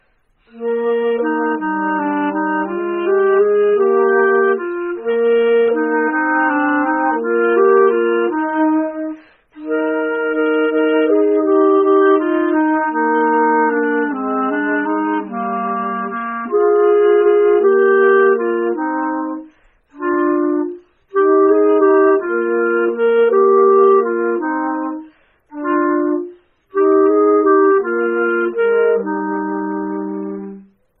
alto flute
clarinet